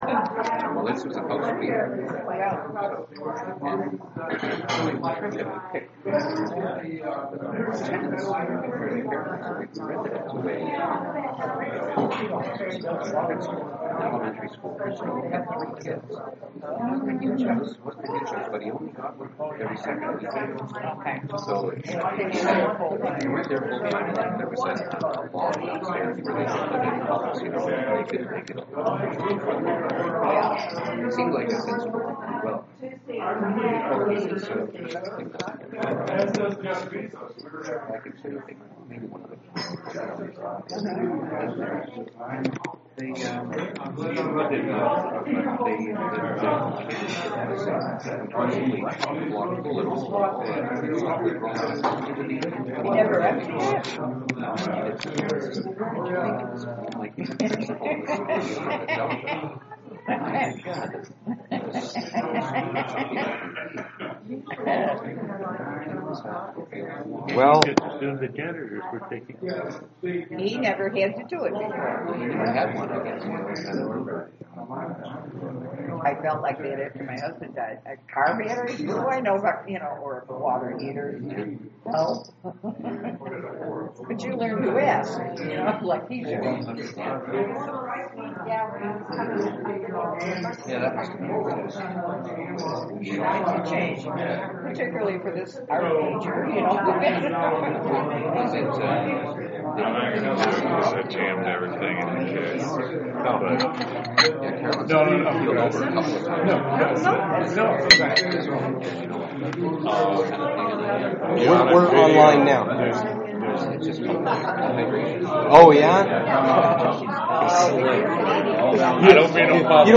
Thanks for showing up on such a wet morning.